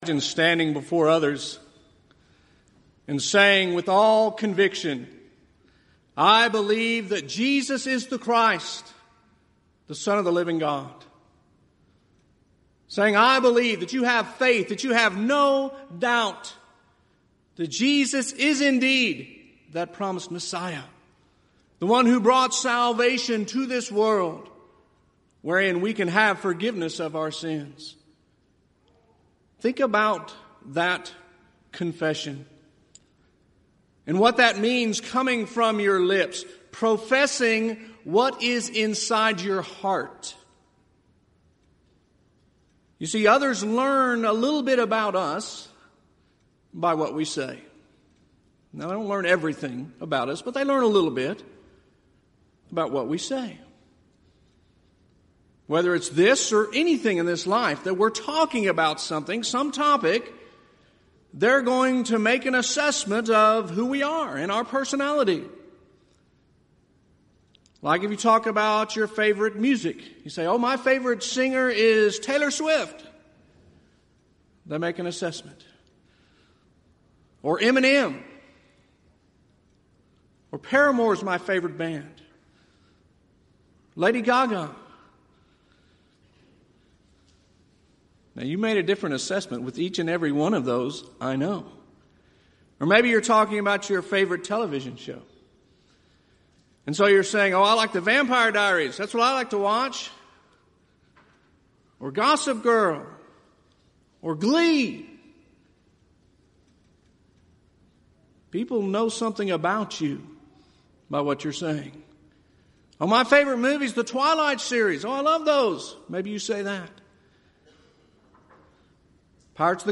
Event: 30th Annual Southwest Bible Lectures
lecture